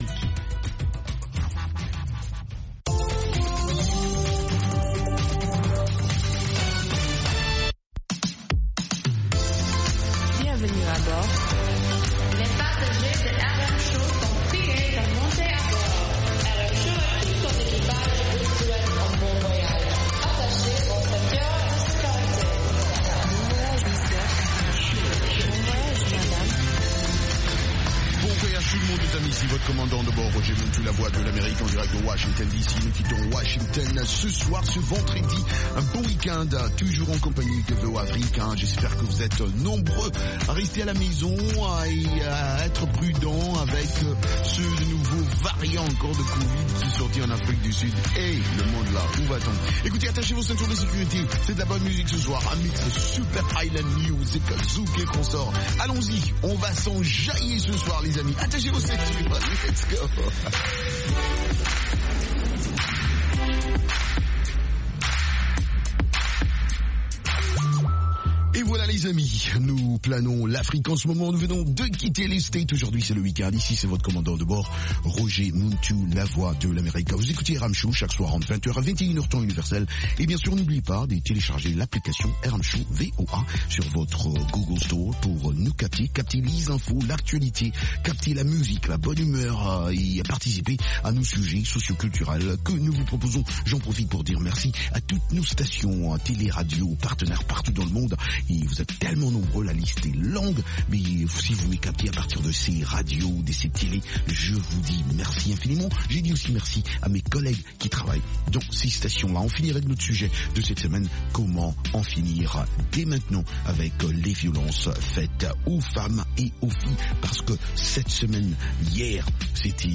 Zouk, Reggae, Latino, Soca, Compas et Afro